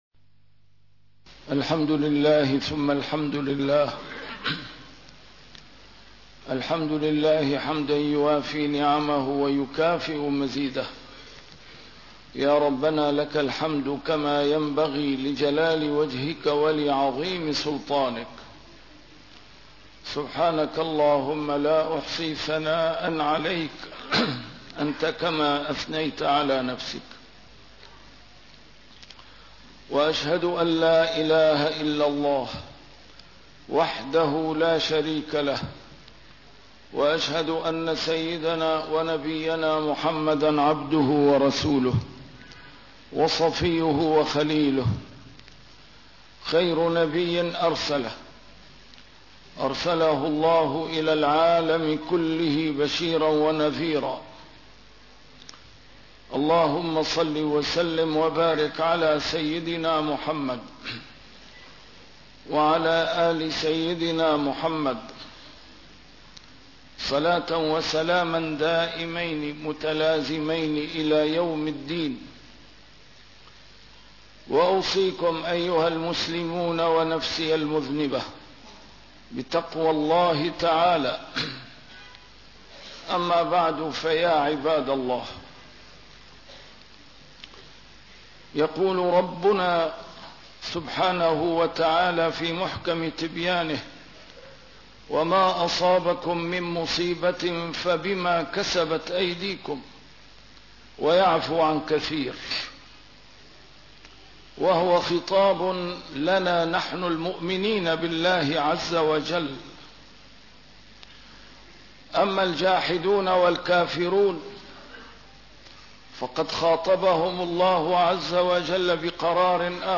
A MARTYR SCHOLAR: IMAM MUHAMMAD SAEED RAMADAN AL-BOUTI - الخطب - لنجعل هذا الأسبوع أسبوع توبة ورجوع إلى الله عز وجل